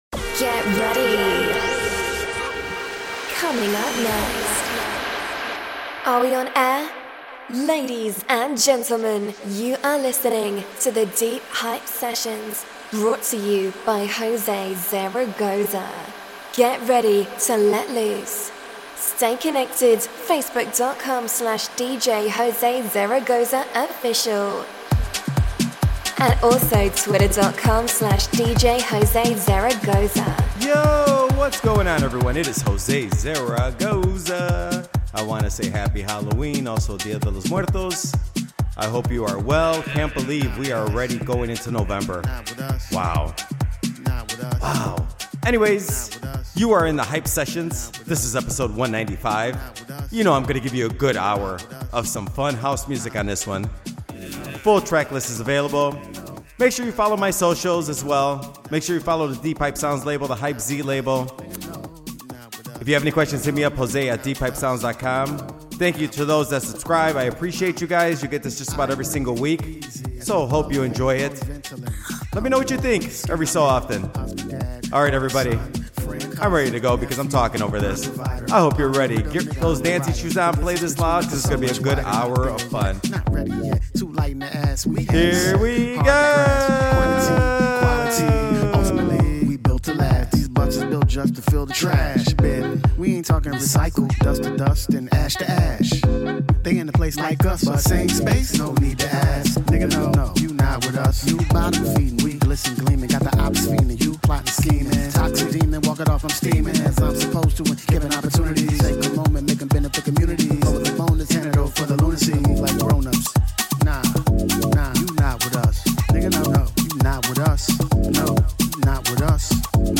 Check out it out, a fun hour of house music!!